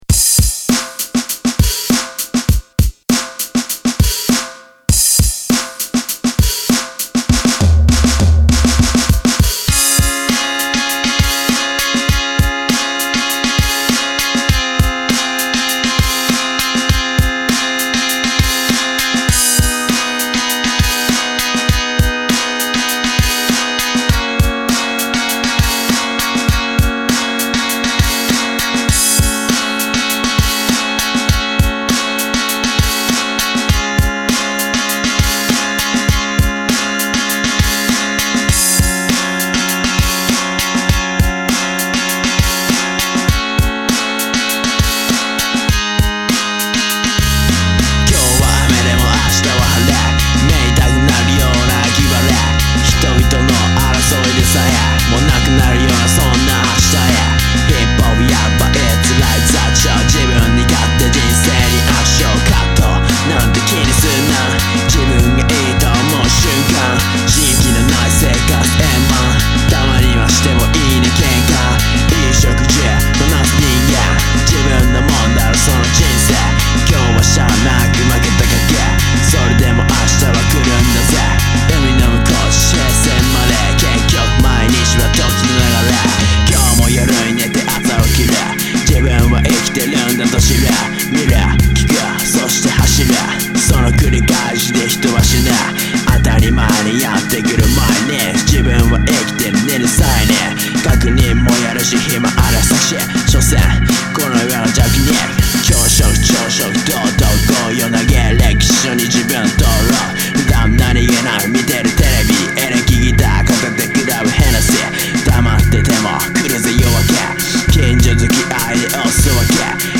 Genre：Hip Hop